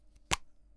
household
Opening Cap of Can of Shaving Foam